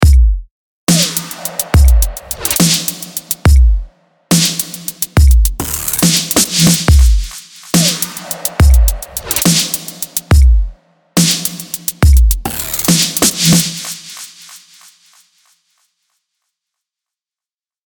I layered a pretty large synth effect sample over the first snare of the loop.
The next snare was preceded by a reverse effect that followed up the epic hit pretty nicely.
The final drum sound!